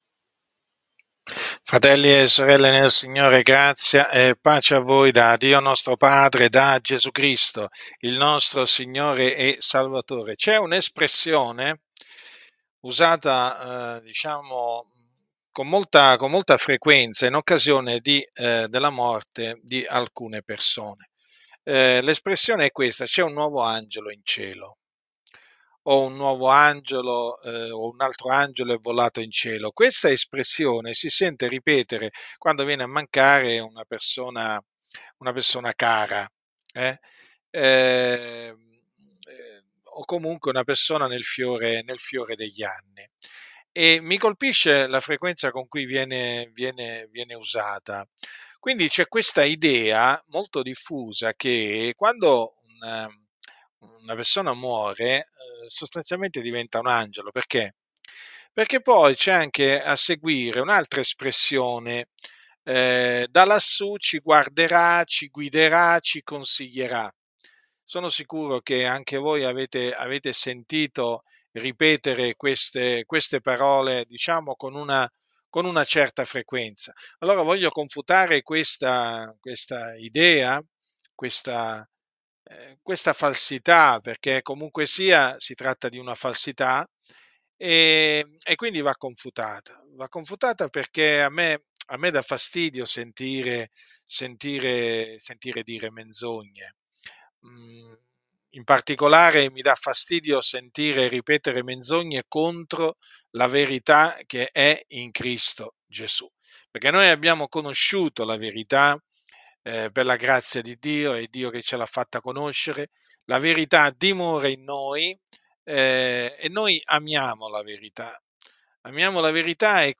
È ora disponibile online la registrazione audio della predicazione
trasmessa in diretta streaming nella serata di ieri.